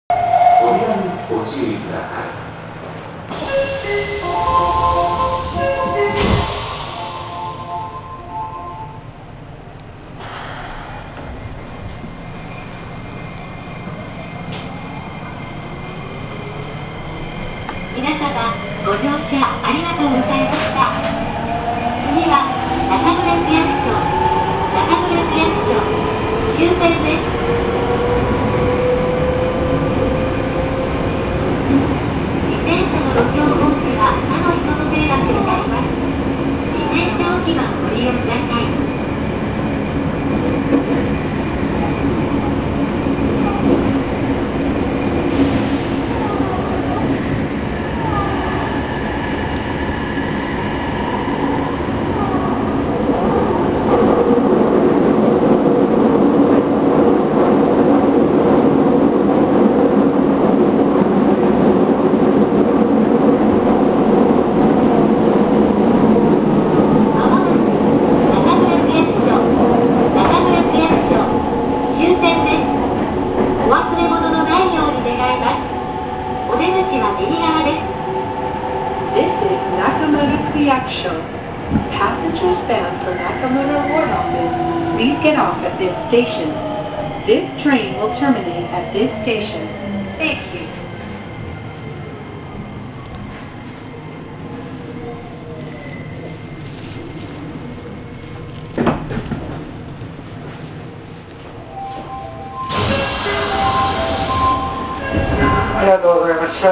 ・6050形走行音
【桜通線】名古屋〜中村区役所（1分47秒：580KB）
近年の日車の新型車両には多い東洋IGBTの音。これといって面白い音ではありません。